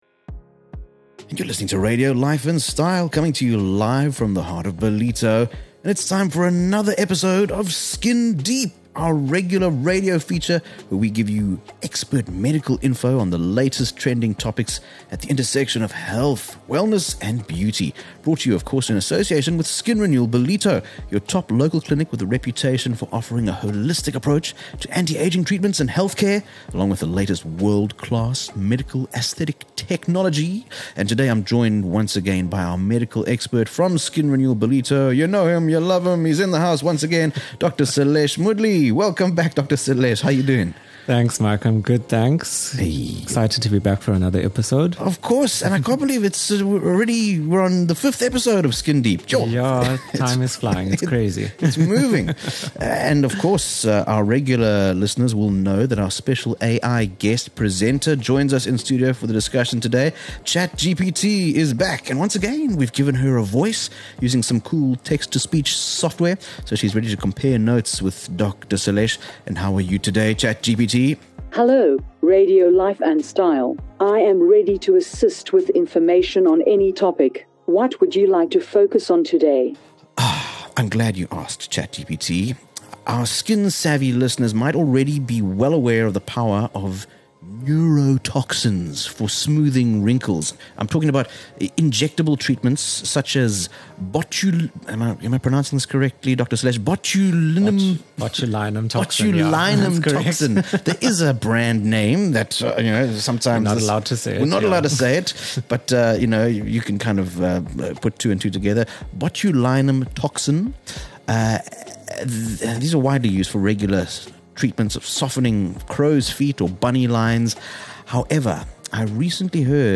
Skin Renewal Ballito presents "Skin Deep” - a regular radio feature giving you expert medical info on the latest trending topics from the world of health, wellness and beauty.